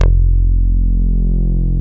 BAS_Prophet5 F2.wav